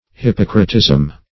Search Result for " hippocratism" : The Collaborative International Dictionary of English v.0.48: Hippocratism \Hip*poc"ra*tism\, n. The medical philosophy or system of Hippocrates.